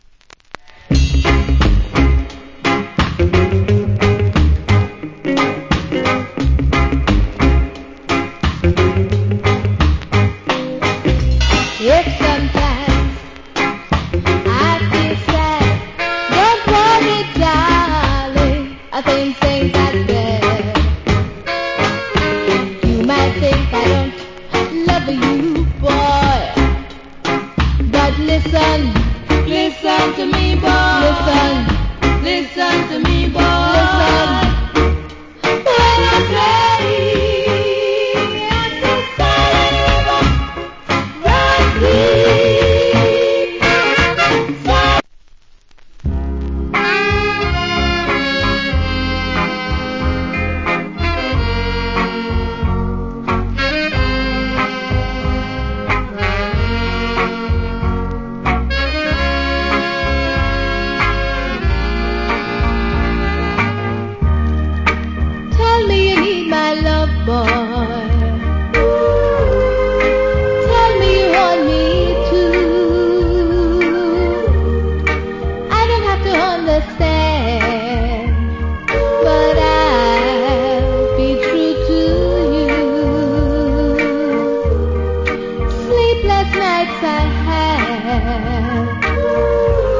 Wicked Female Rock Steady Vocal.